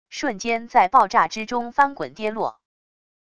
瞬间在爆炸之中翻滚跌落wav音频